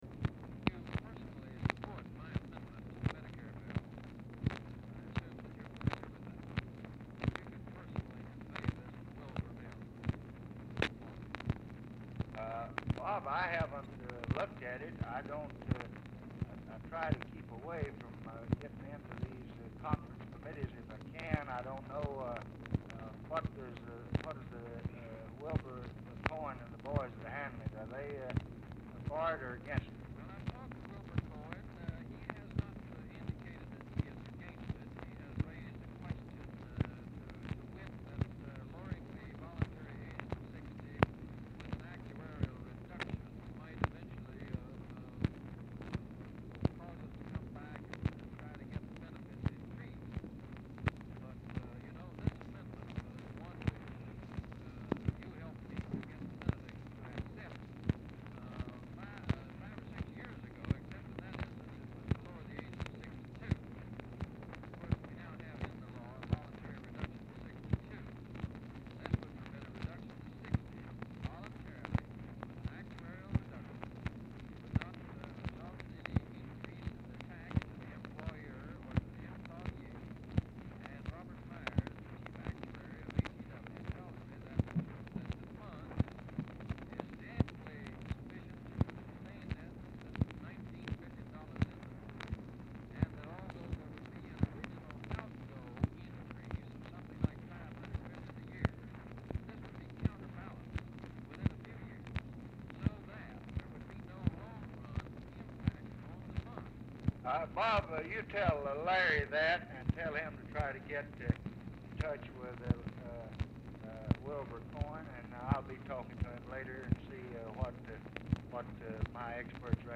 Telephone conversation # 8343, sound recording, LBJ and ROBERT BYRD, 7/15/1965, 2:50PM
RECORDING STARTS AFTER CONVERSATION HAS BEGUN; POOR SOUND QUALITY; BYRD IS ALMOST INAUDIBLE
Format Dictation belt